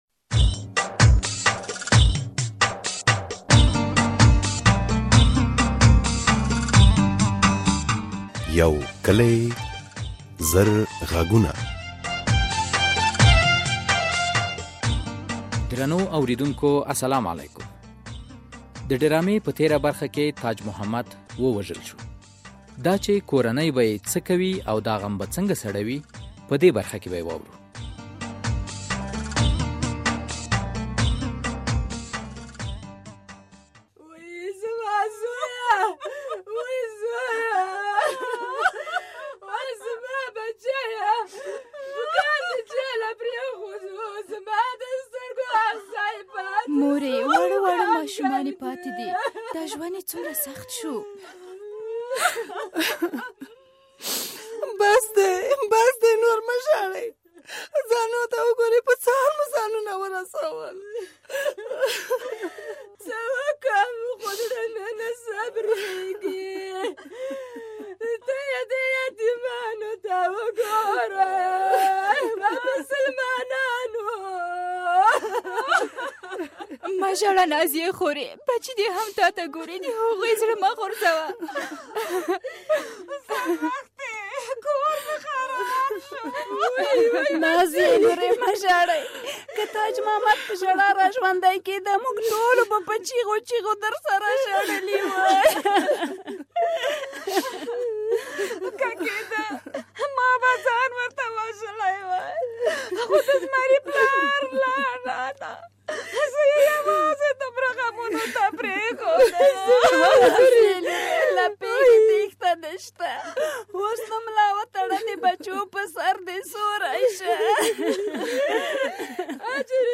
یو کلي او زر غږونه ډرامه هره اوونۍ د دوشنبې په ورځ څلور نیمې بجې له ازادي راډیو خپریږي.